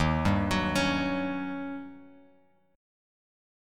Eb7#9 chord